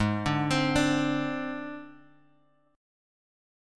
G# Major Flat 5th